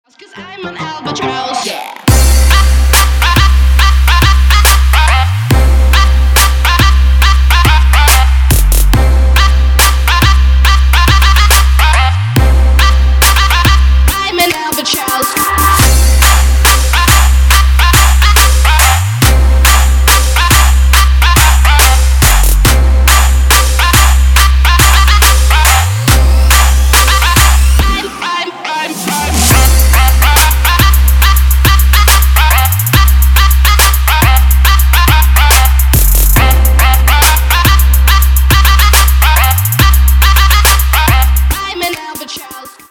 Trap
Bass
bounce